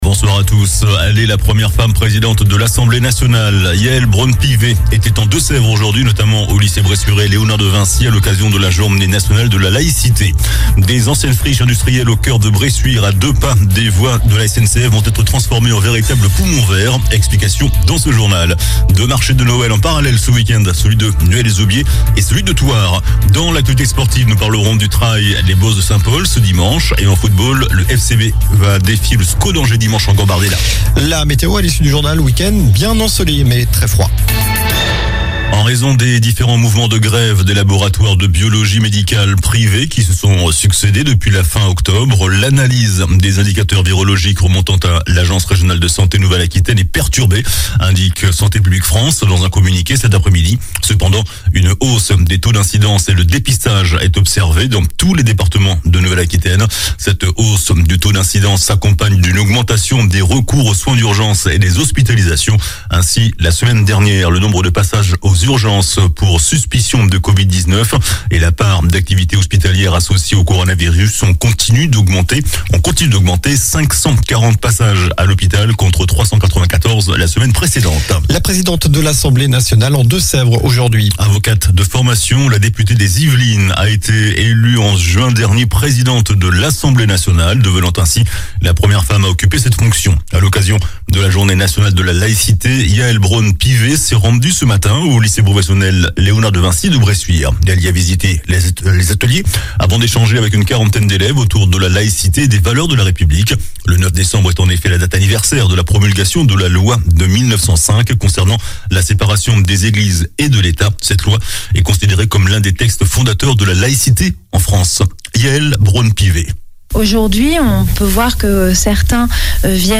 COLLINES LA RADIO : Réécoutez les flash infos et les différentes chroniques de votre radio⬦
Journal du vendredi 09 décembre (soir)